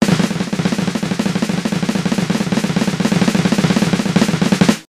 よくイベントやテレビで使用されている賞の発表などで使用される効果音「ドドドドドン〜！」